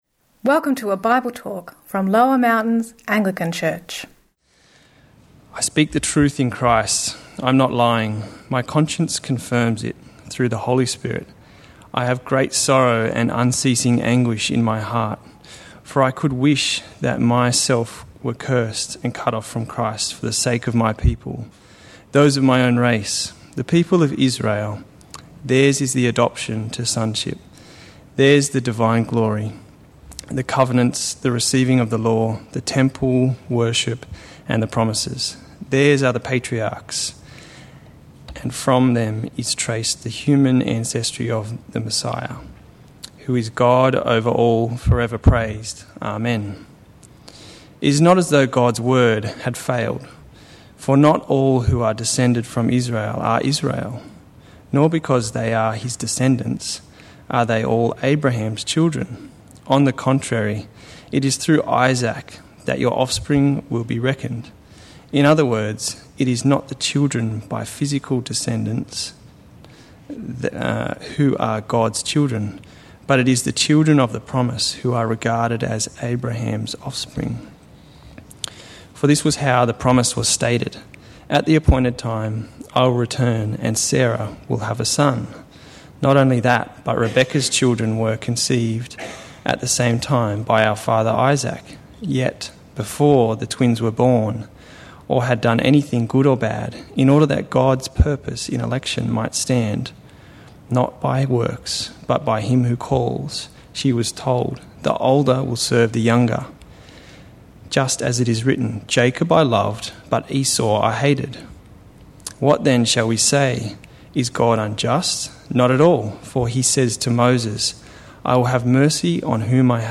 Sermon – Home Goal: Rejecting the given gospel (Romans 9:1-10:13)